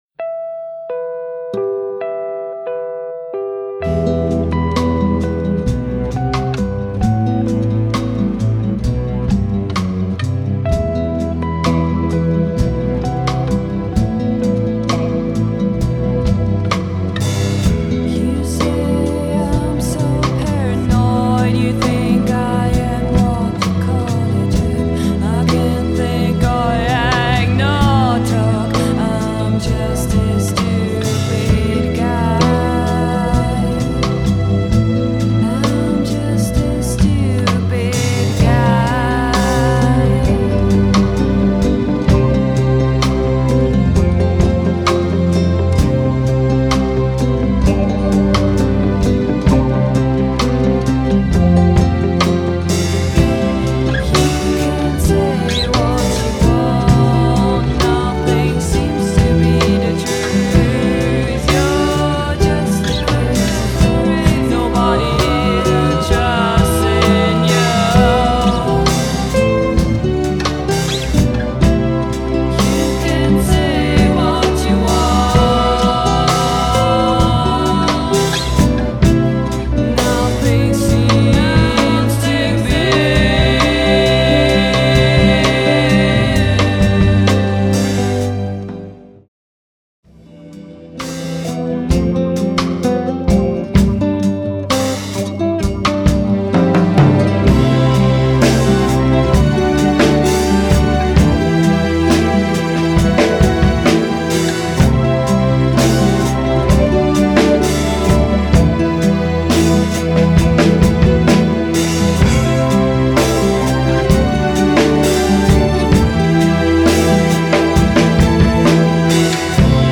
(hard 'n heavy, mal rockig und manchmal auch soft )